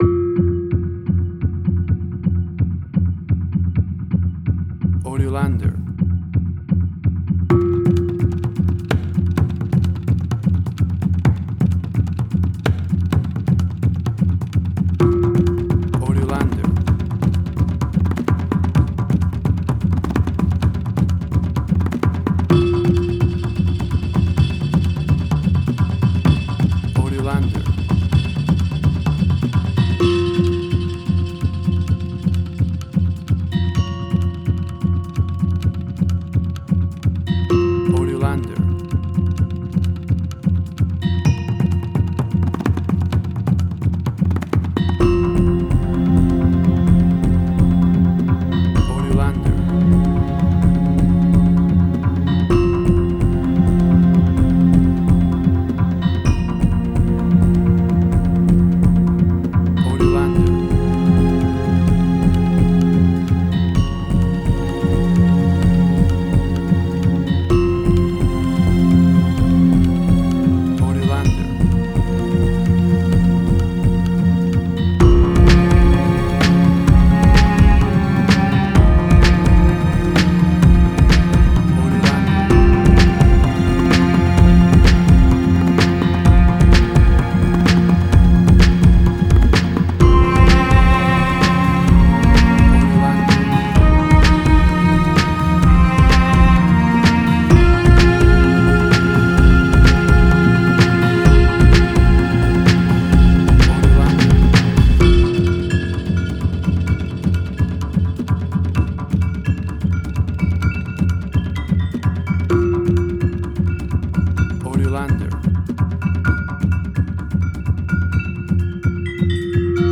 Suspense, Drama, Quirky, Emotional.
Tempo (BPM): 128